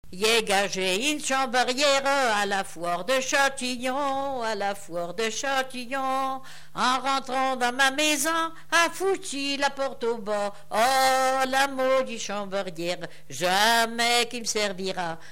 Genre laisse
Enquête Arexcpo en Vendée-C.C. Mortagne
Pièce musicale inédite